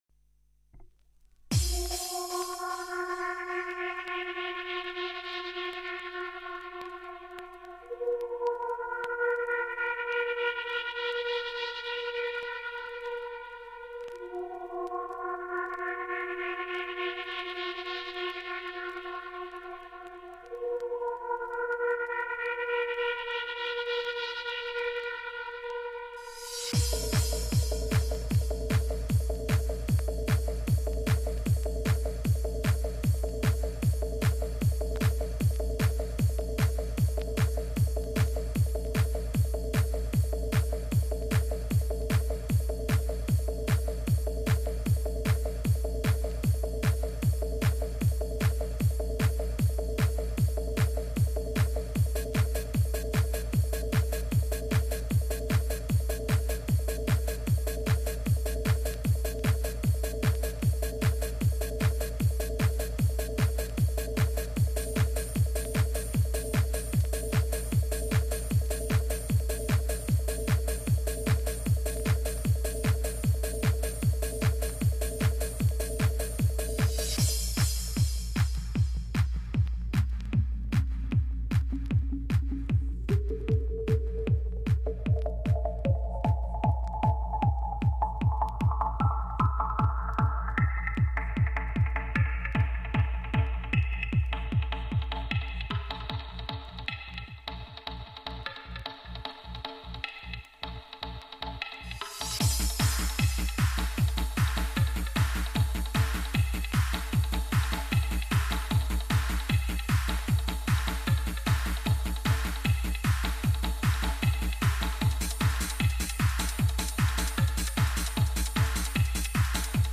Trance Hard House Vocals